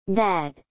英 [dæd]
英式发音